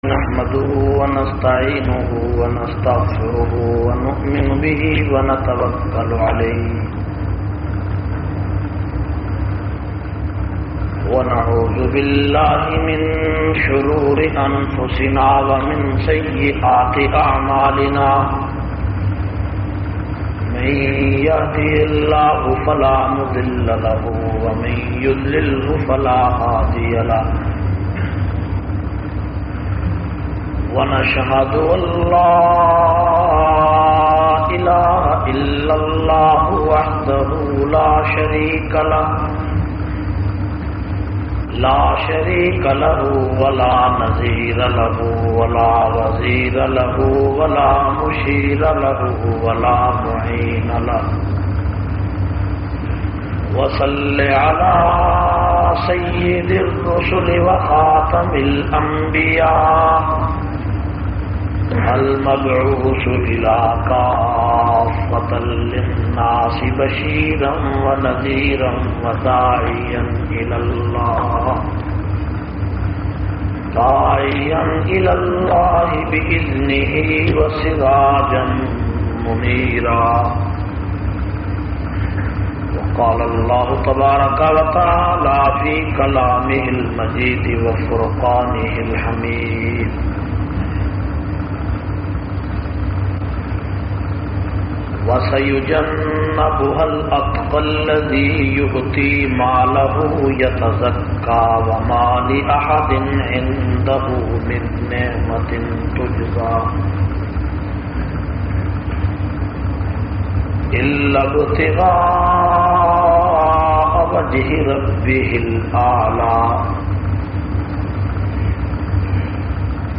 628- Azmat e Sahaba Conference- Masjid Hashim, Al Fujairah UAE.mp3